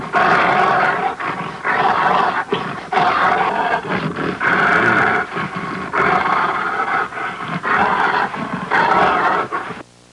Attacking Grizzly Bear Sound Effect
Download a high-quality attacking grizzly bear sound effect.
attacking-grizzly-bear.mp3